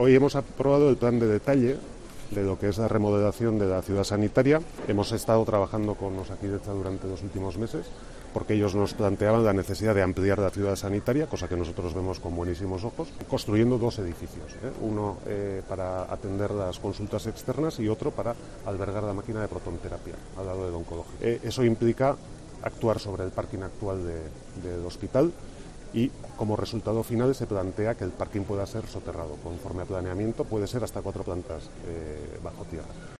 Eneko Goia, alcalde de San Sebastián